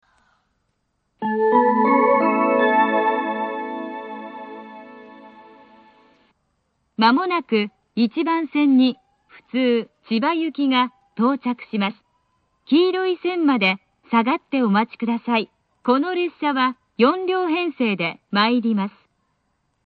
１番線接近放送 普通千葉行の放送です。